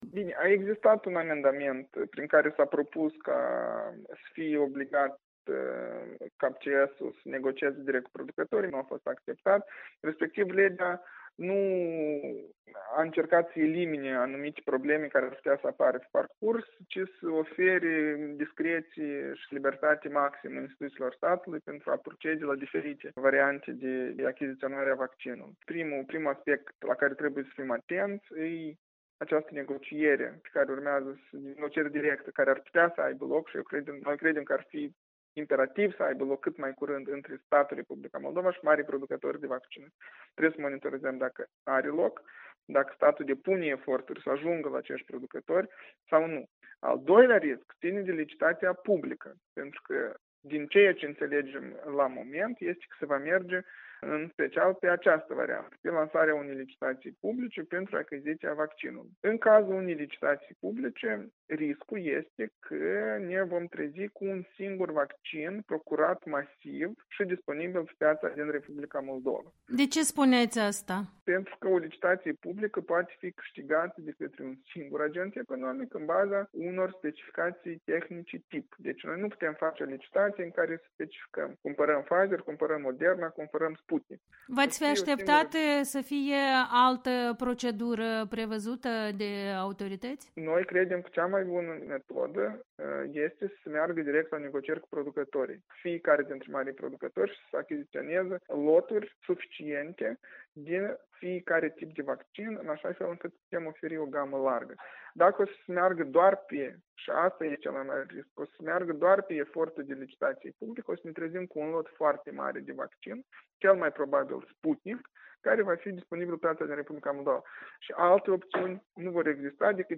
Interviu cu Dan Perciun